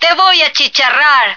flak_m/sounds/female1/est/F1burnbaby.ogg at efc08c3d1633b478afbfe5c214bbab017949b51b